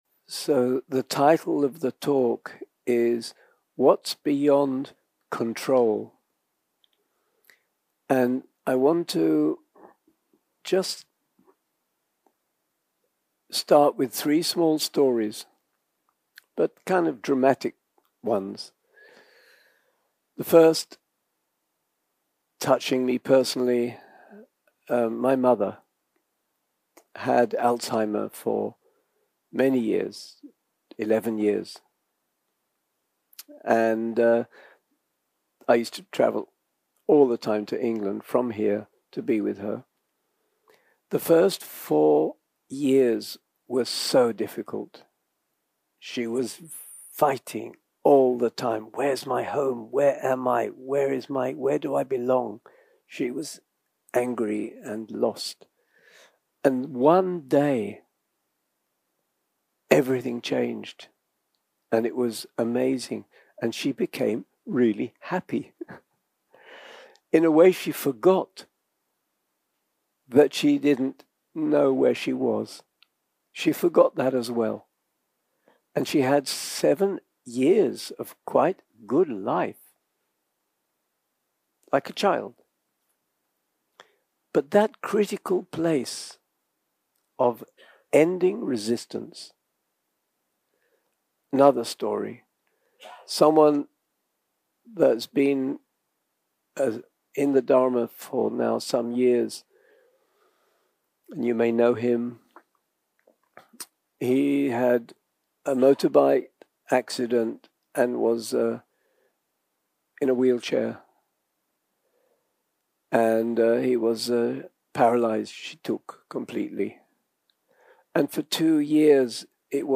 יום 2 - הקלטה 3 - ערב - שיחת דהרמה - Beyond Control